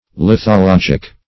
Search Result for " lithologic" : The Collaborative International Dictionary of English v.0.48: Lithologic \Lith`o*log"ic\, Lithological \Lith`o*log"ic*al\, a. [Cf. F. lithologique.] 1.